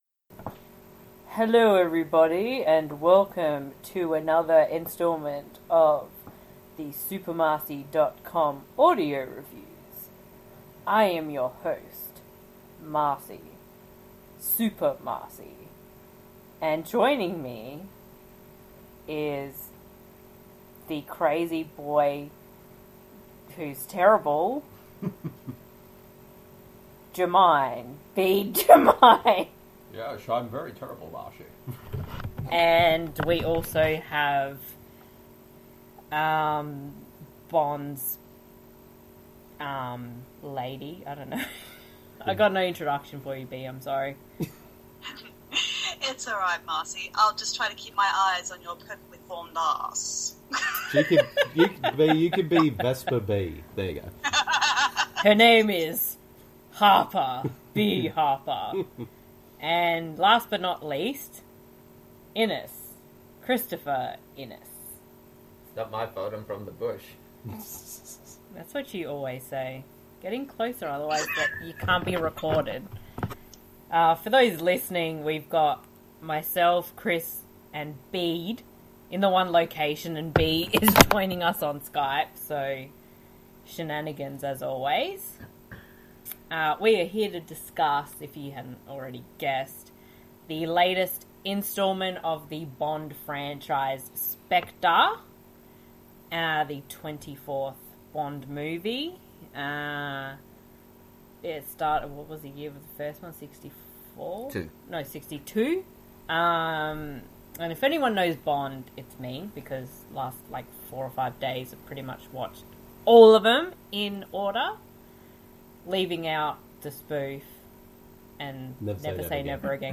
*WARNING THIS AUDIO REVIEW DOES CONTAIN MILD SPOILERS*